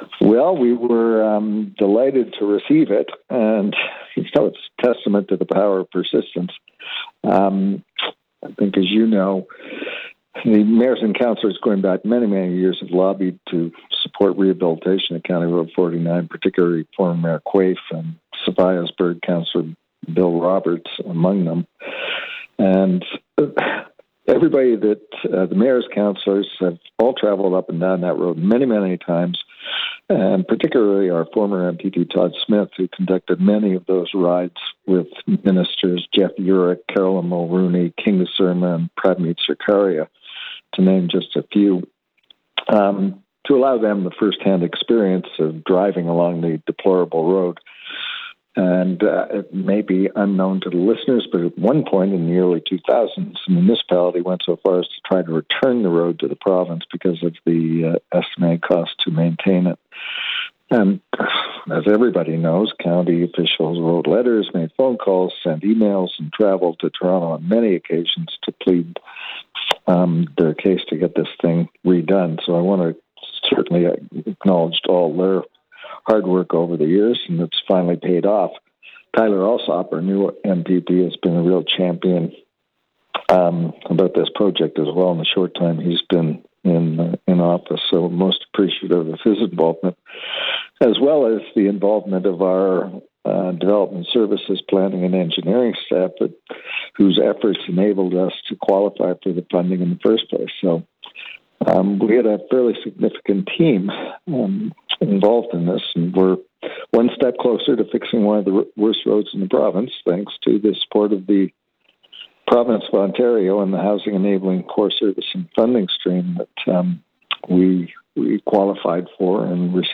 You can listen to the full interview with Mayor Steve Ferguson below:
ferguson-interview.wav